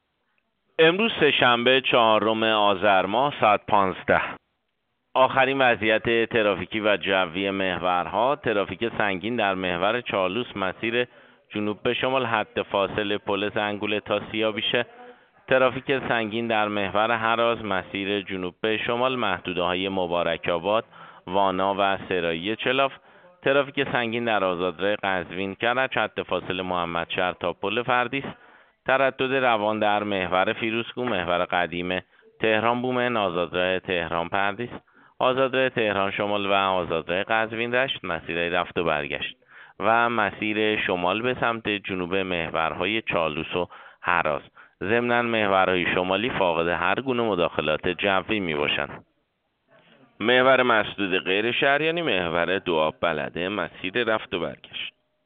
گزارش رادیو اینترنتی از آخرین وضعیت ترافیکی جاده‌ها ساعت ۱۵ چهارم آذر